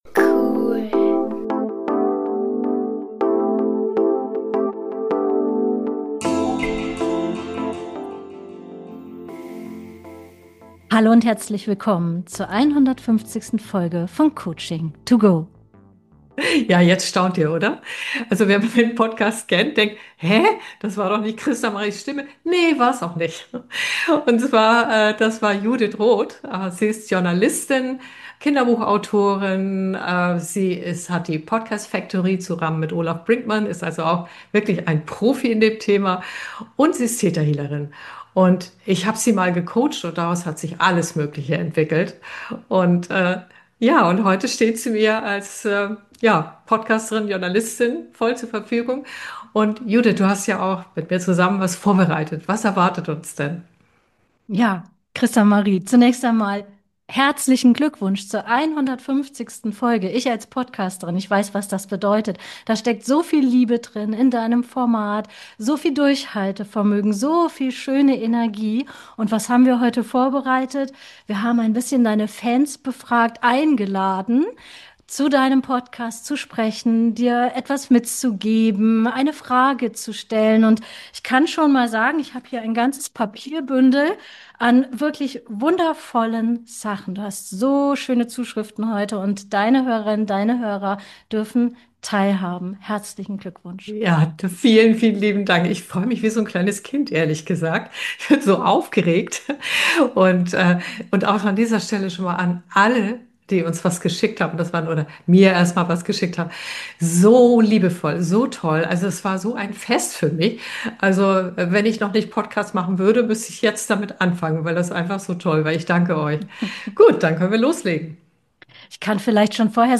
Weiter gehts mit der Beantwortung von Fragen von Hörer*Innen zu Selbstcoaching, Führung, Umgang mit Künstlicher Intelligenz (KI), Buchtips und Energiearbeit. Außerdem werden im Podcast noch Experimente live vor laufendem Mikrophon gemacht.